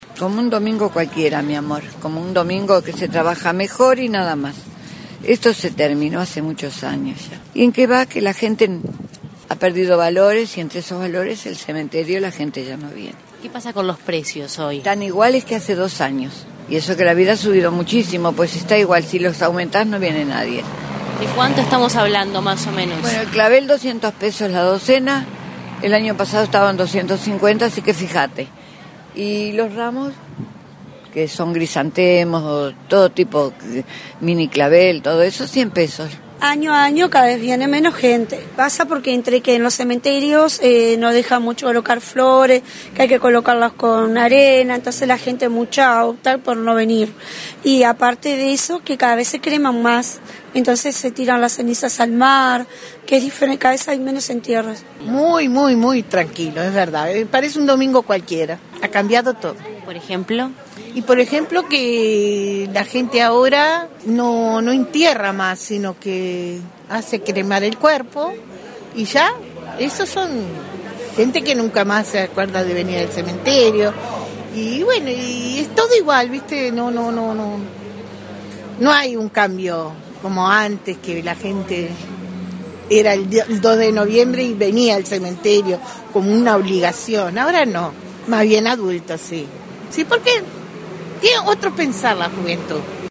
Vendedores